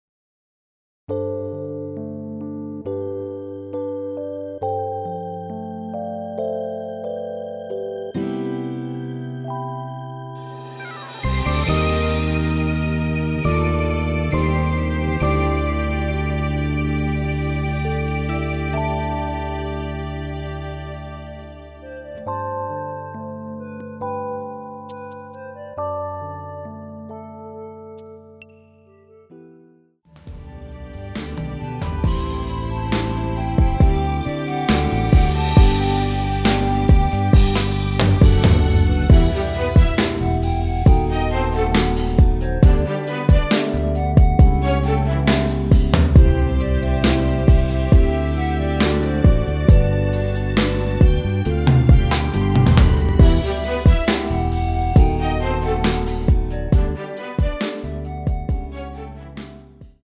원키에서(-1)내린 (1절+후렴)으로 진행되는 멜로디 포함된 MR입니다.(미리듣기 확인)
◈ 곡명 옆 (-1)은 반음 내림, (+1)은 반음 올림 입니다.
앞부분30초, 뒷부분30초씩 편집해서 올려 드리고 있습니다.